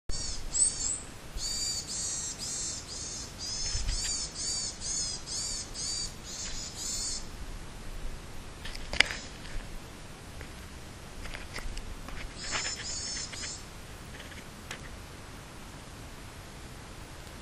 わが家の近くでも小鳥たちがやってきて鳴き声を聞かせてくれました。
(録音した鳴き声は)たぶんヤマガラです。